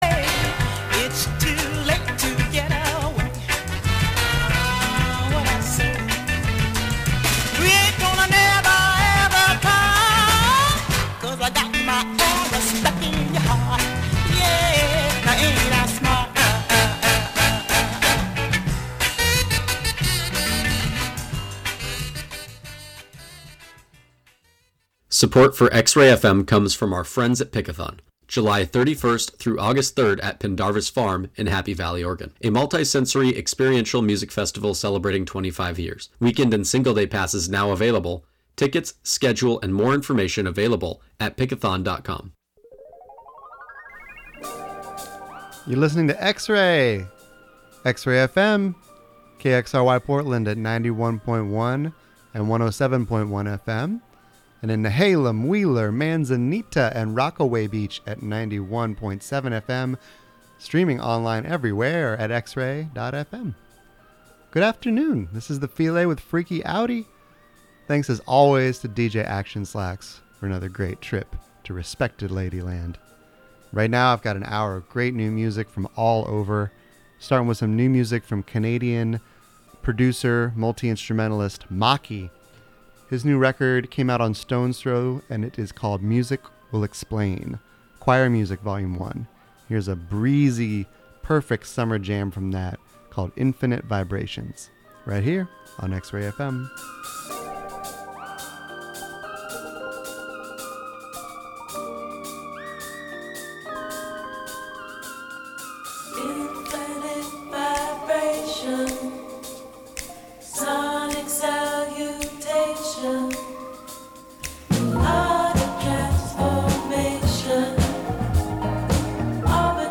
New and newly unearthed jams from all over to put you in a dancing mood.